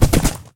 gallop2.ogg